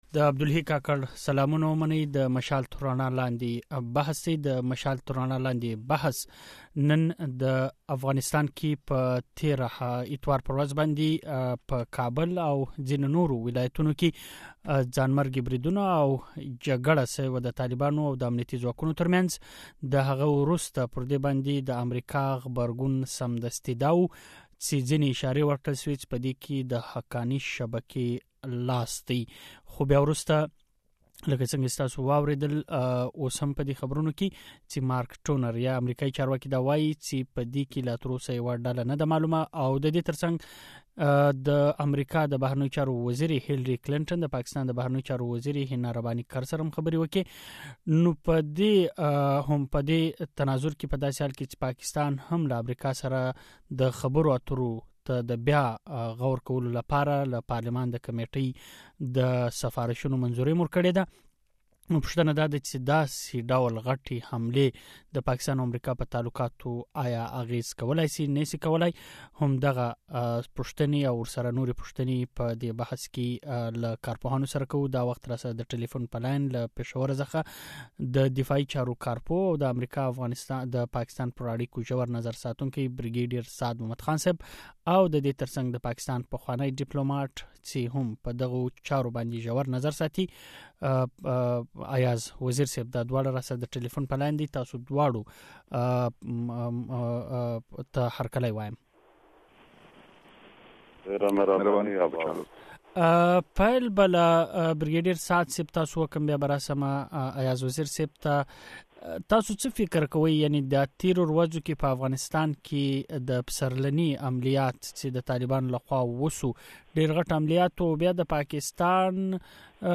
دمشال تر رڼا لاندې بحث